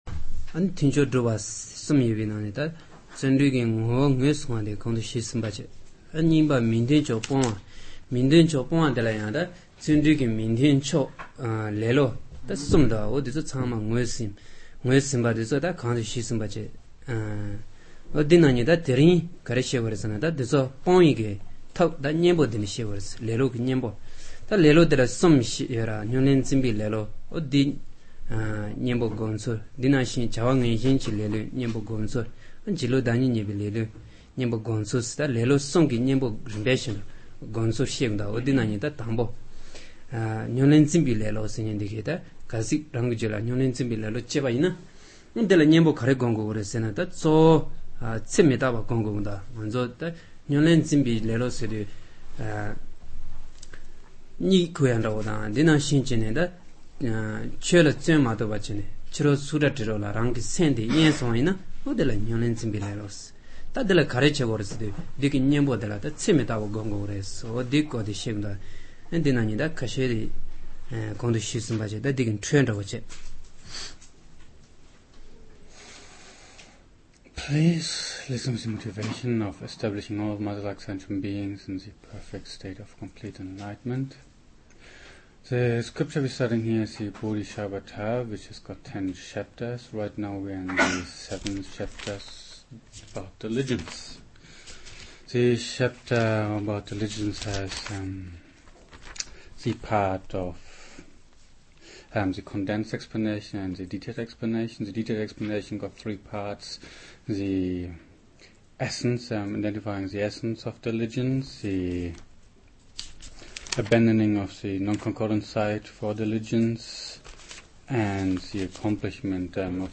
Recorded during Shedra East 2006-2007 in Pharping / Nepal